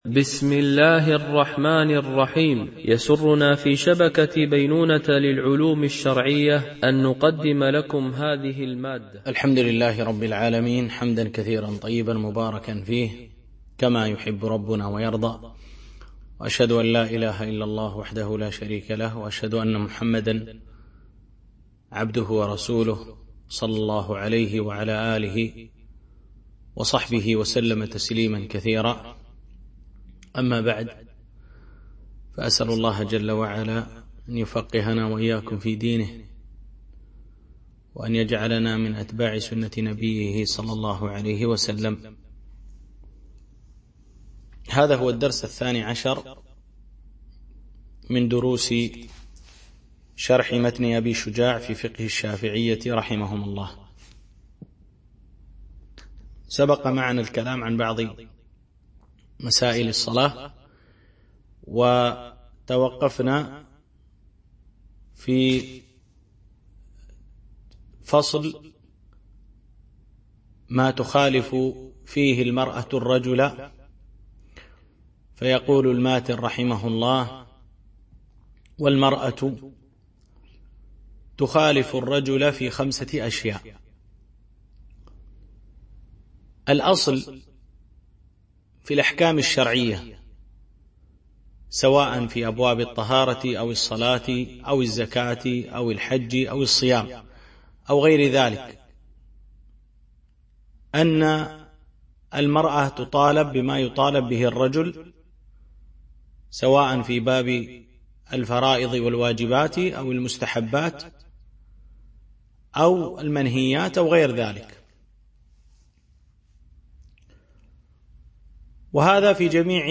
شرح متن أبي شجاع في الفقه الشافعي ـ الدرس 12